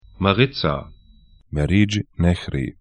Maritza ma'rɪtsa Meriç nehri mɛ'rɪʤ 'nɛçri tr Fluss / stream 40°44'N, 26°02'E